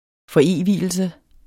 Udtale [ fʌˈeˀˌviˀəlsə ]